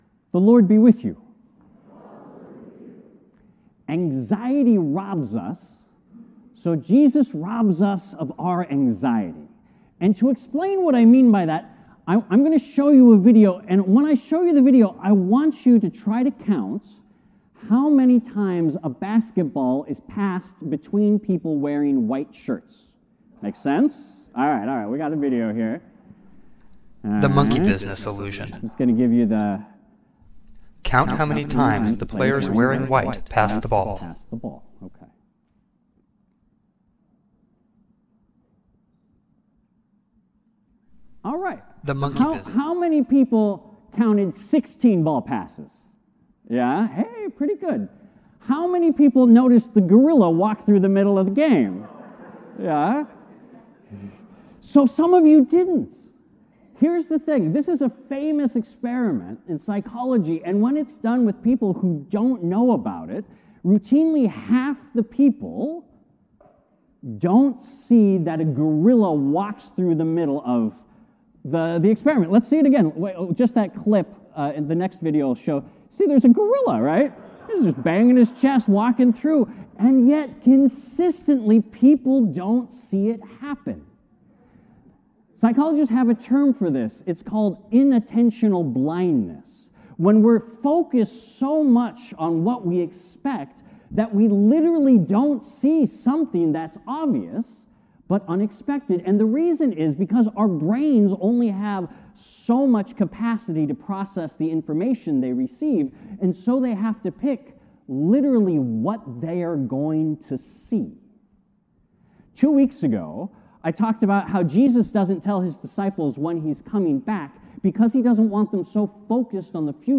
Sermons from Faith Lutheran Church | Faith Lutheran Church